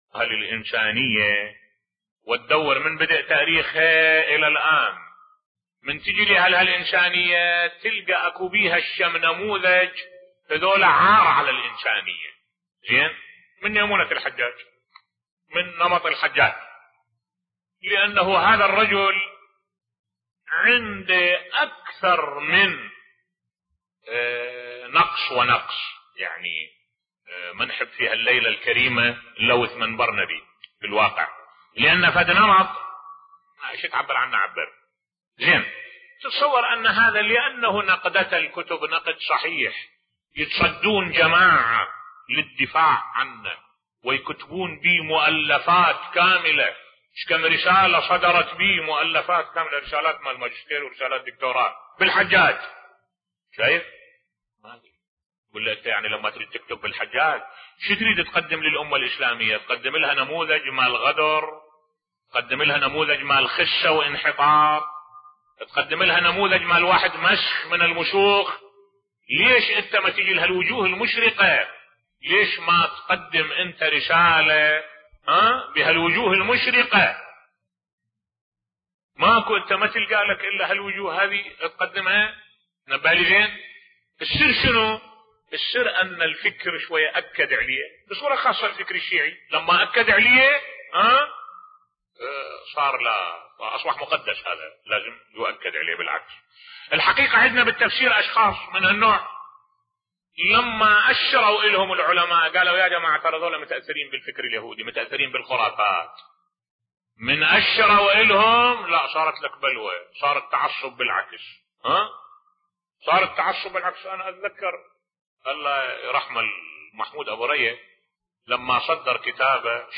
ملف صوتی الدكتور محمود أبو رية كاد أن يُقتل بسبب كتابه شيخ المضيرة بصوت الشيخ الدكتور أحمد الوائلي